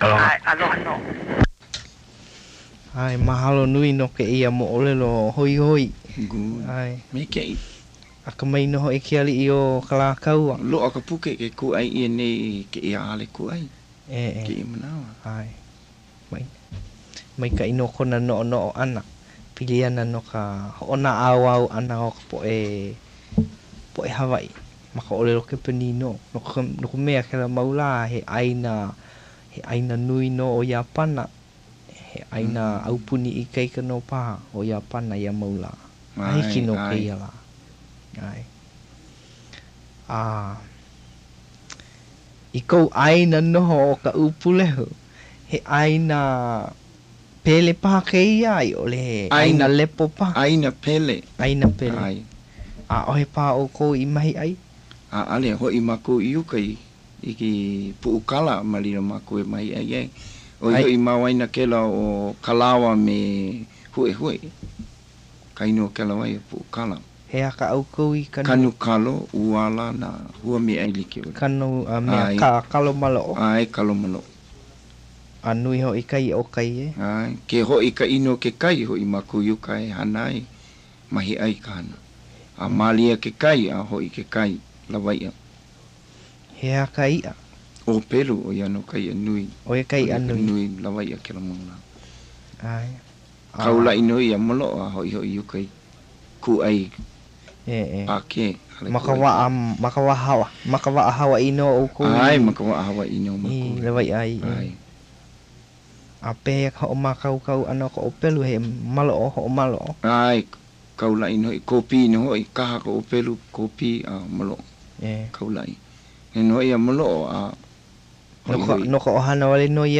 Interviewer
digital wav file (44.1 kHz/16 bit); orginally recorded at KCCN studios onto reel-to-reel tape, then digitized to mp3, then converted to wav
Hawaiʻi; recordings made in Honolulu, Hawaiʻi